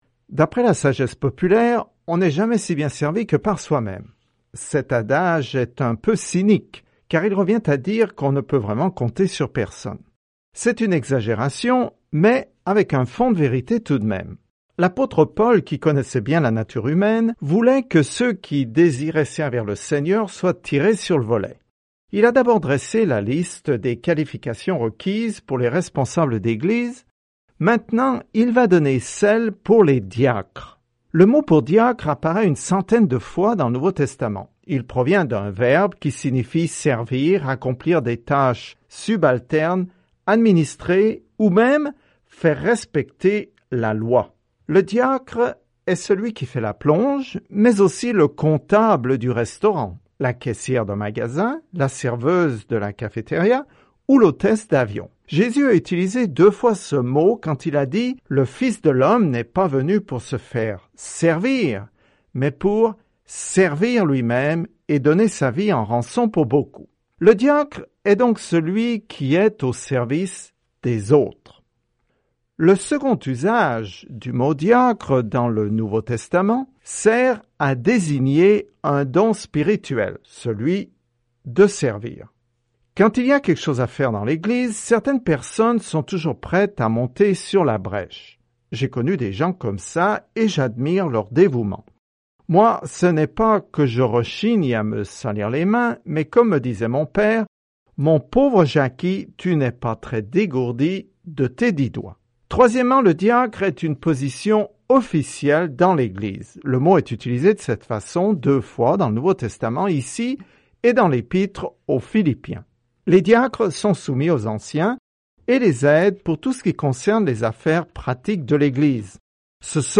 Écritures 1 Timothée 3:8-16 Jour 7 Commencer ce plan Jour 9 À propos de ce plan La première lettre à Timothée fournit des indications pratiques démontrant que quelqu’un a été transformé par l’Évangile – de vrais signes de piété. Parcourez quotidiennement 1 Timothée en écoutant l’étude audio et en lisant des versets sélectionnés de la parole de Dieu.